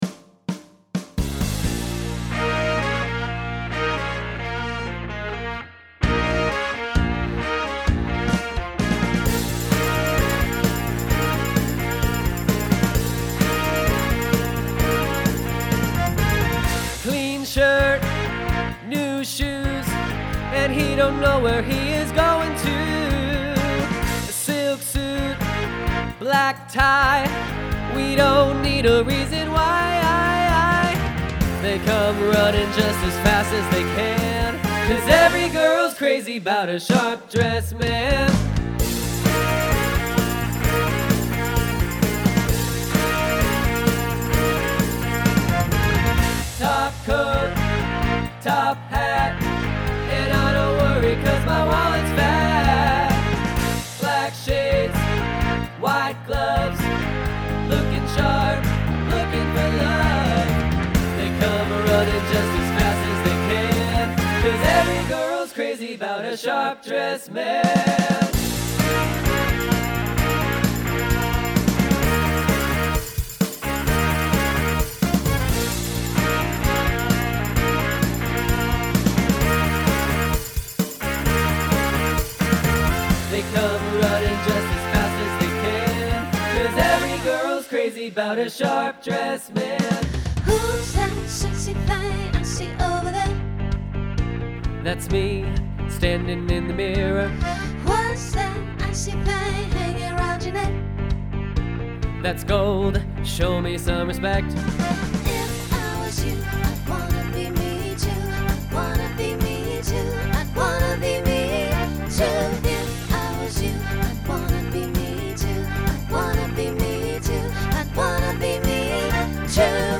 Pop/Dance , Rock Instrumental combo
Transition Voicing Mixed